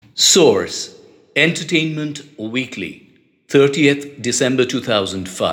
English voice sample: